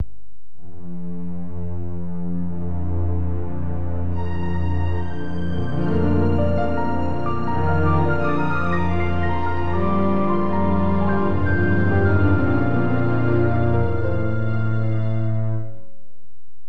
Windows 起動時用効果音
comment: シンセサイザーにオーケストラ音色ボードを取り付けた時に、
とりあえず何かやってみたくて適当に音を重ねてみました。